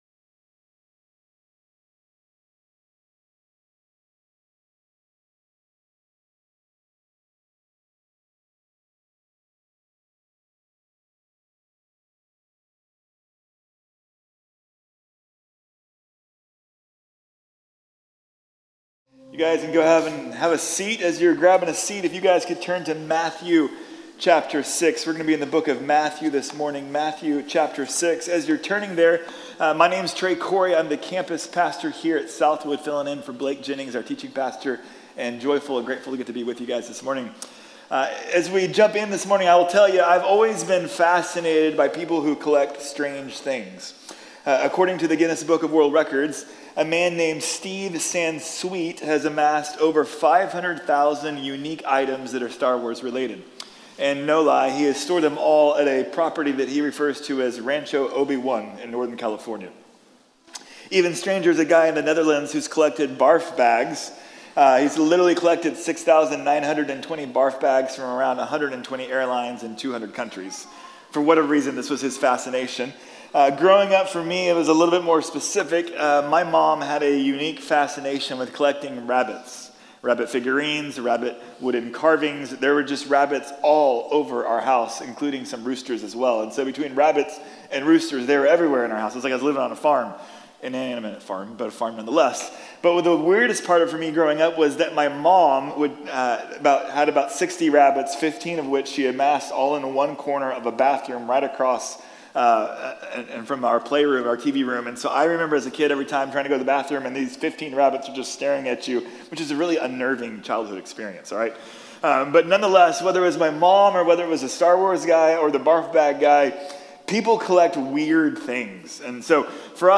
¿Por qué damos? | Sermón de la Iglesia Bíblica de la Gracia